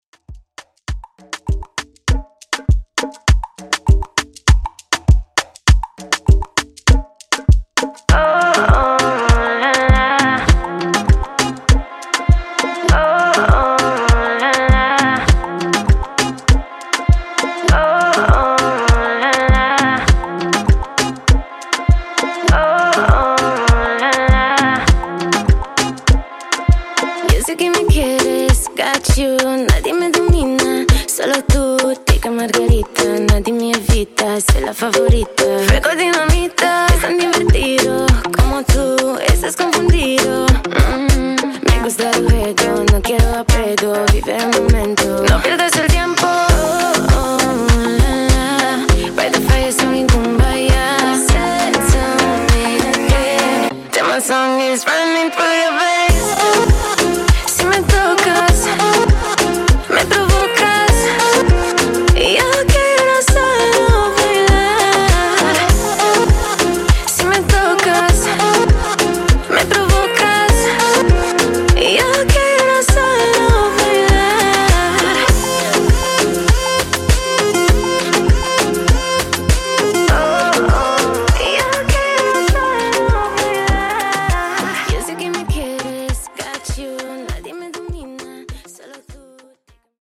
Afrobeat Remix)Date Added